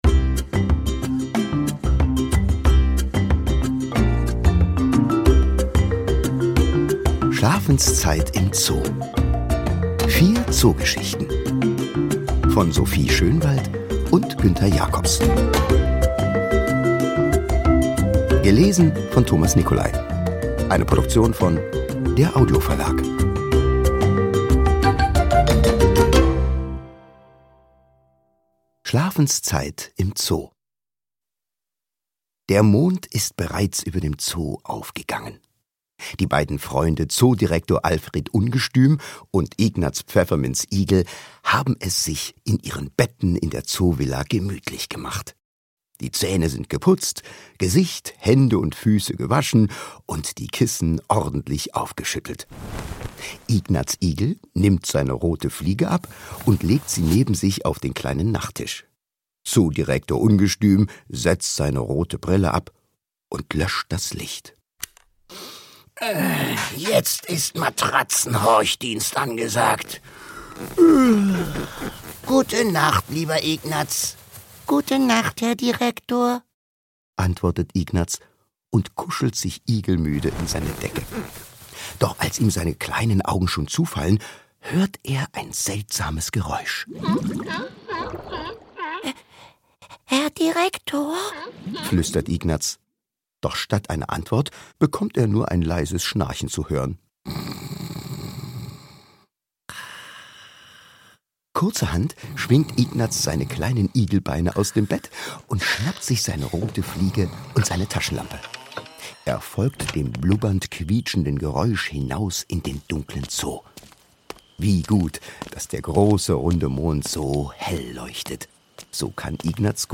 Ungekürzte szenische Lesungen mit Musik mit Thomas Nicolai (1 CD)
Thomas Nicolai (Sprecher)
Der Entertainer wird auch »Mann mit den tausend Stimmen« genannt.